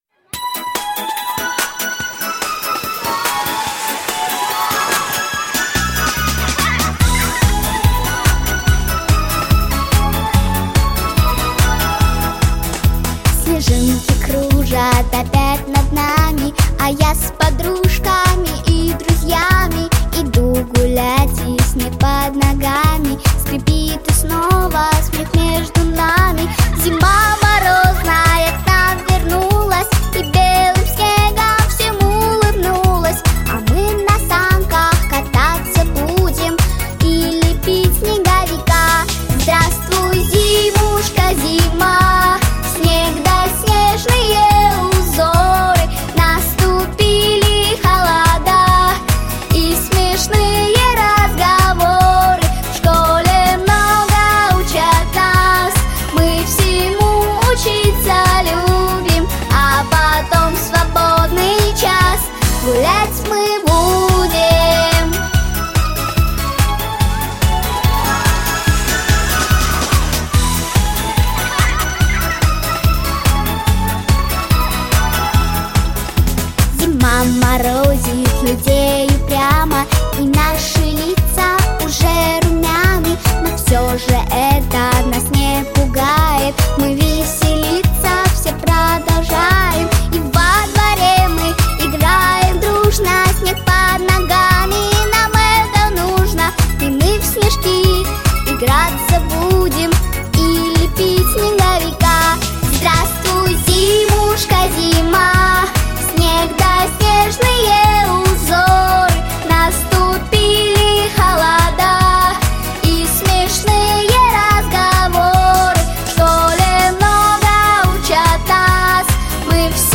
🎶 Детские песни / Времена года / Песни про Зиму 🥶